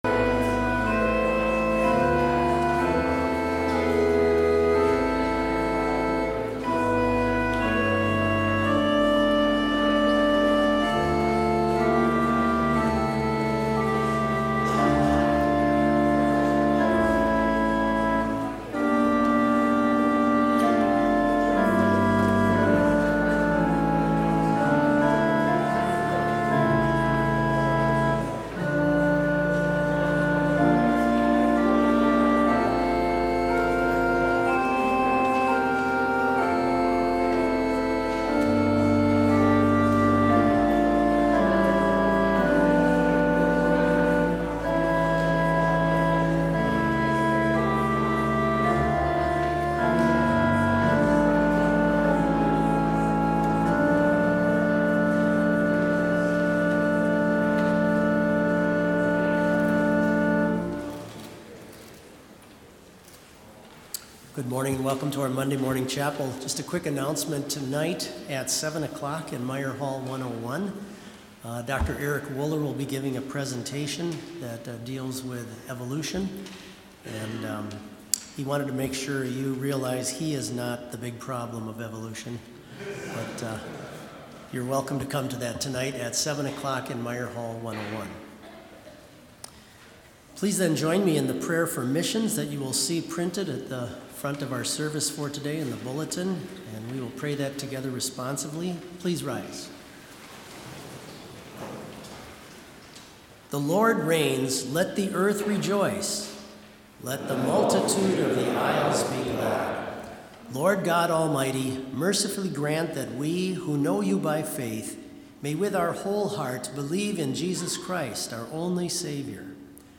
Complete service audio for Chapel - January 24, 2022